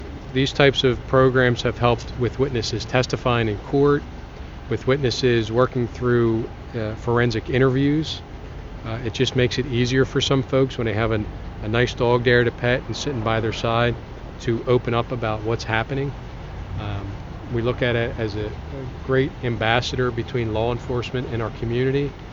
DA Robert Manzi said that they used money seized in drug investigations to pay for the equipment.